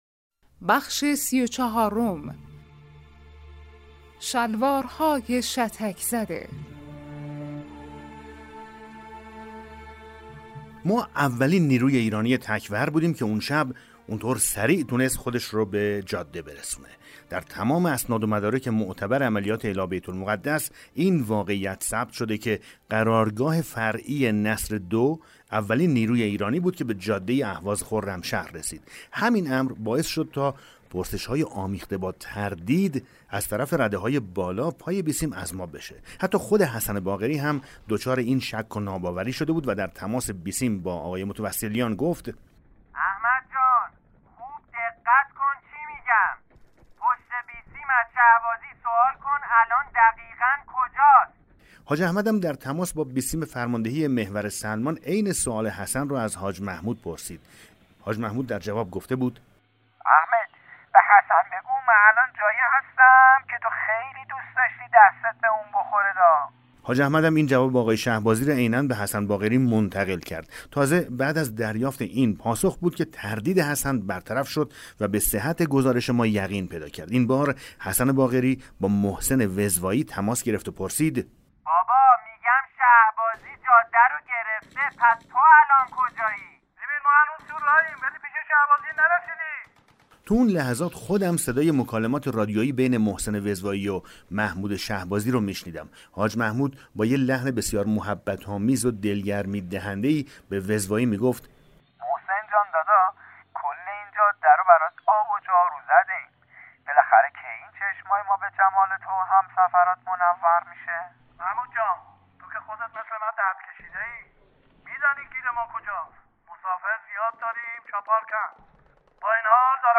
کتاب صوتی پیغام ماهی ها، سرگذشت جنگ‌های نامتقارن حاج حسین همدانی /قسمت 34